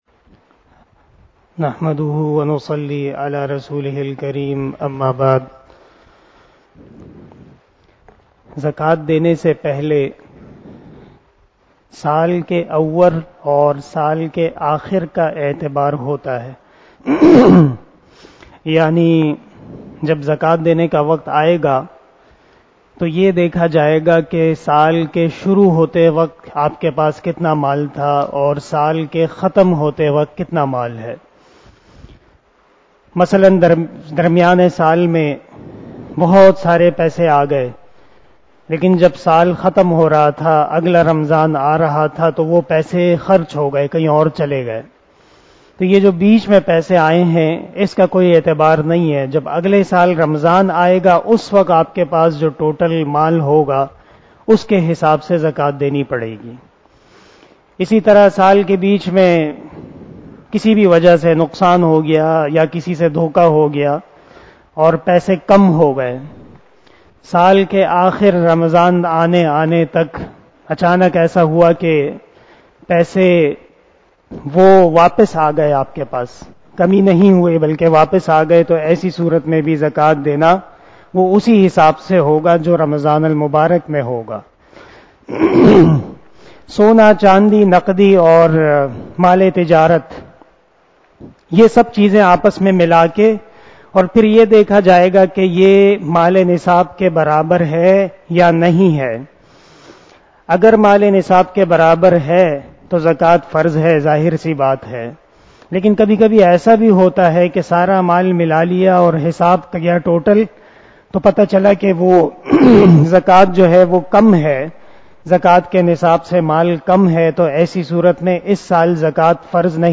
034 After Traveeh Namaz Bayan 09 April 2022 ( 08 Ramadan 1443HJ) Saturday